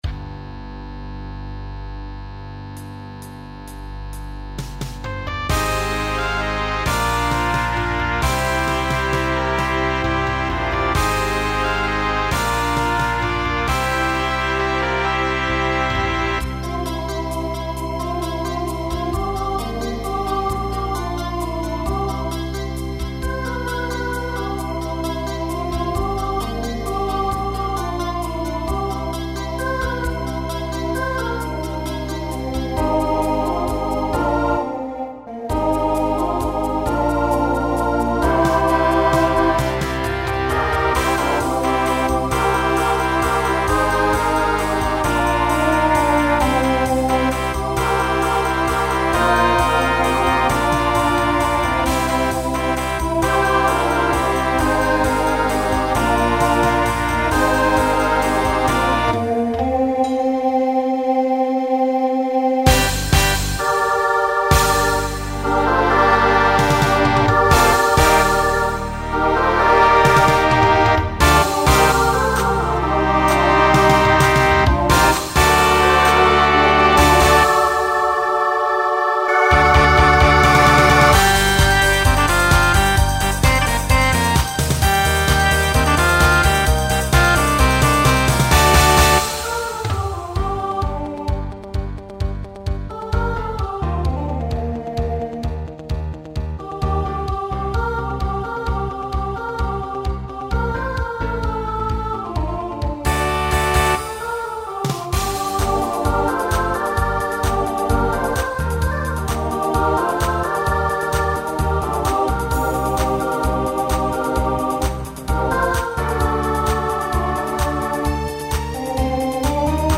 Genre Pop/Dance Instrumental combo
Voicing SSA